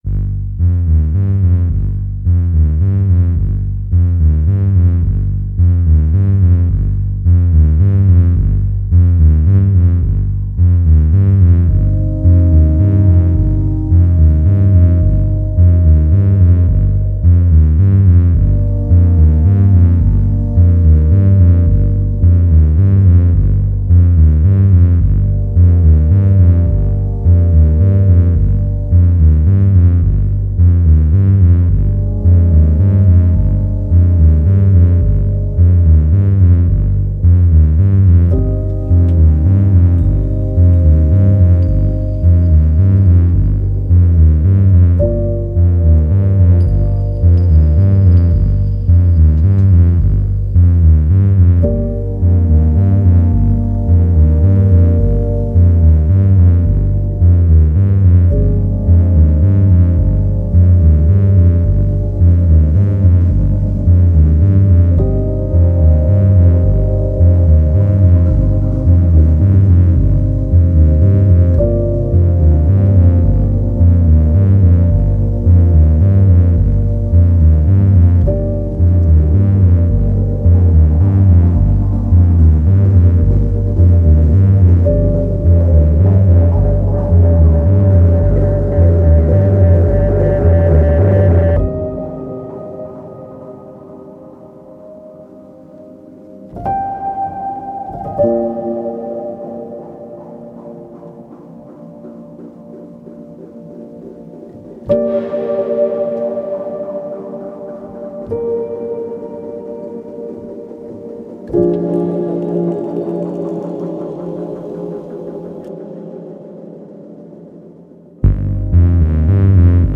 Otherworldly sounds refract and oscillate.